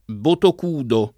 botocudo [ botok 2 do ]